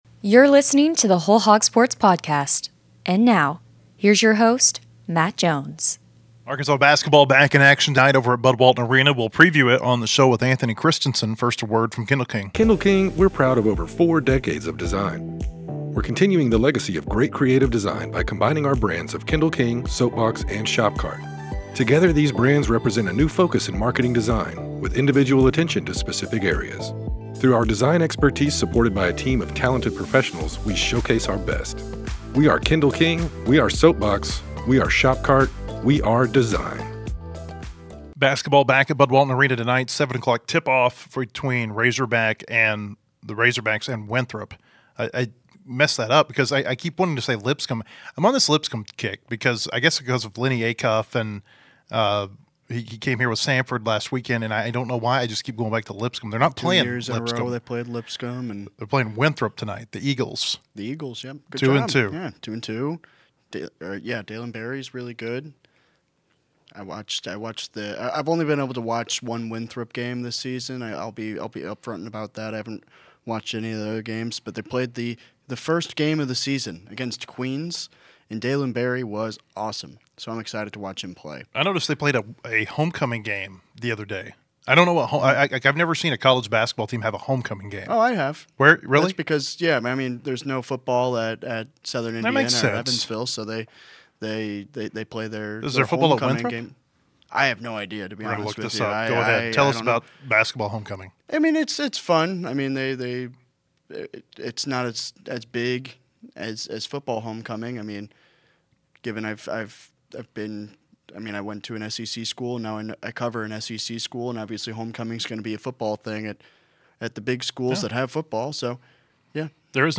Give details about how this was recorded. is also in the studio to talk Arkansas football.